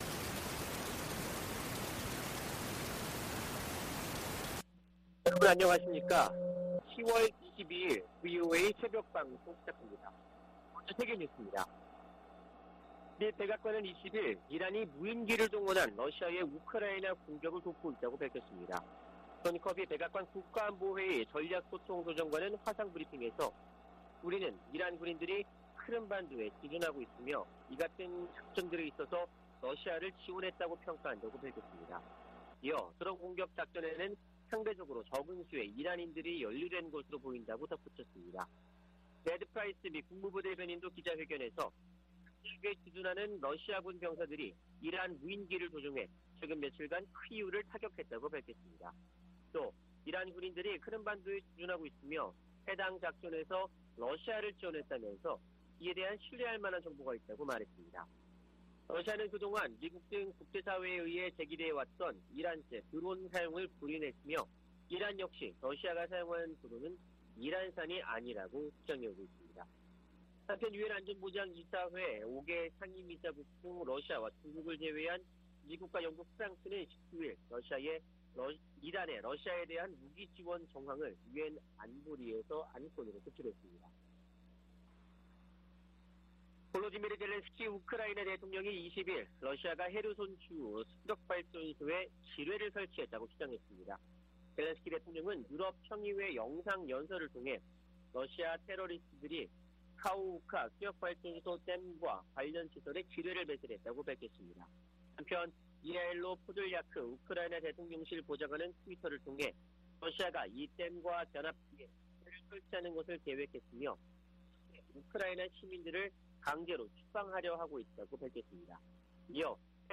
VOA 한국어 '출발 뉴스 쇼', 2022년 10월 22일 방송입니다. 조 바이든 미국 대통령은 모든 방어역량을 동원해 한국에 확장억제를 제공하겠다는 약속을 확인했다고 국무부 고위당국자가 전했습니다. 미 국방부가 북한의 잠재적인 추가 핵실험 준비 움직임을 주시하고 있다고 밝혔습니다.